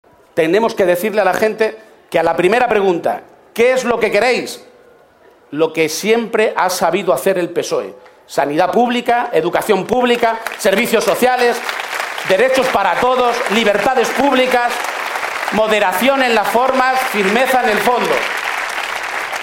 Cerca de tres mil personas acudieron al mitin celebrado en la Caseta de los Jardinillos del Recinto de la Feria de Albacete
Cortes de audio de la rueda de prensa